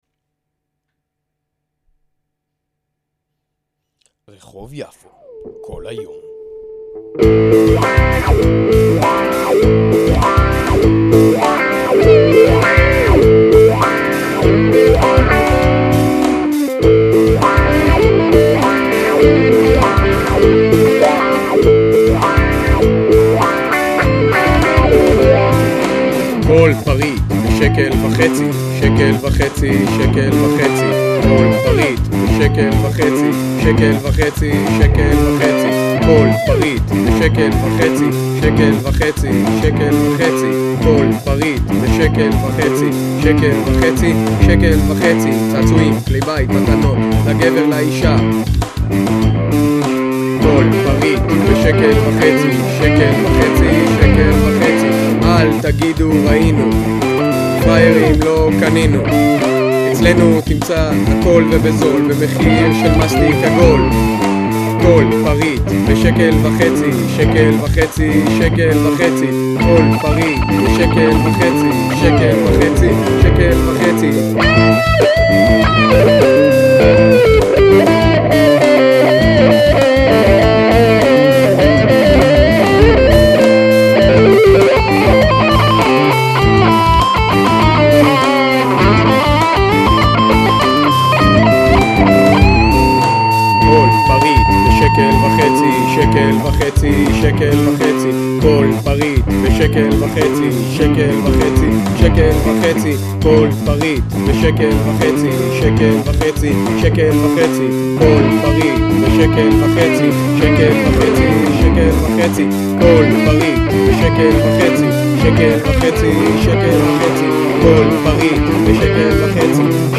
גיטרות
תכנות תופים
בס
שירה: אין בשיר...
הגיטרה החשמלית צורמת לי..